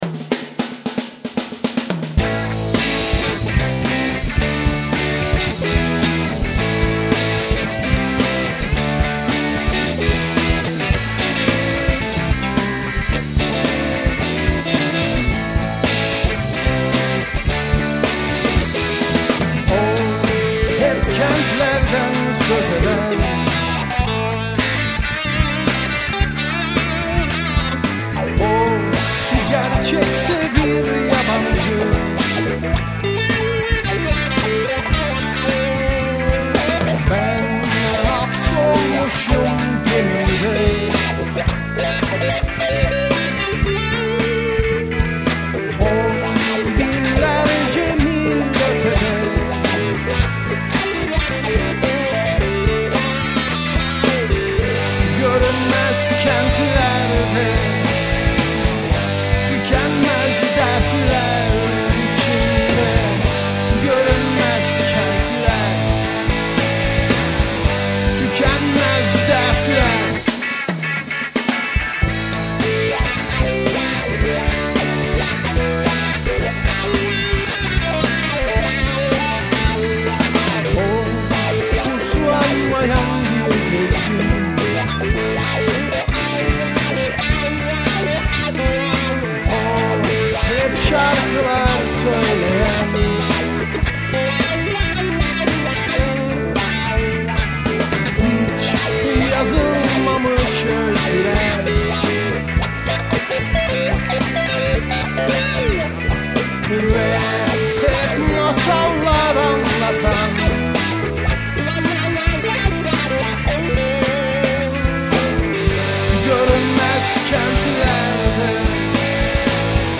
Vokal, elektrik ritim gitar
Bas gitar
Elektrik lead gitar
Davul